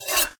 sword_in.ogg